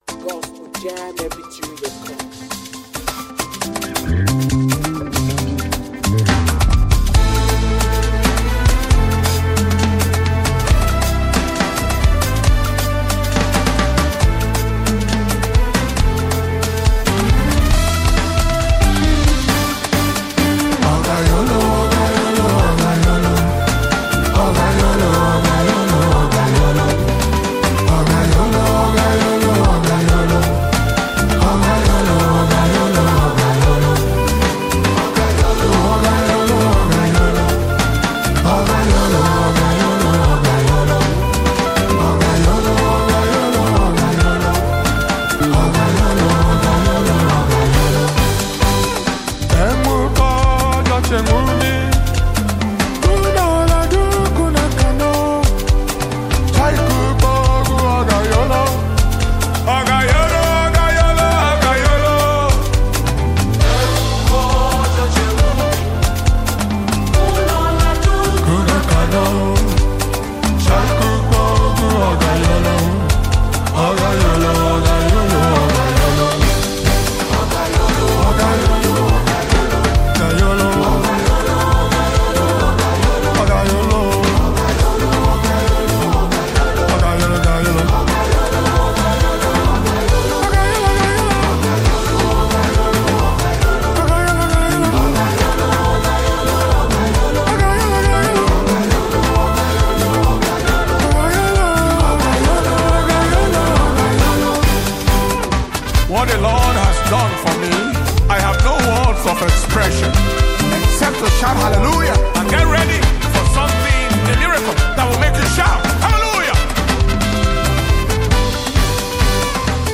African Gospel Music
powerful Praise song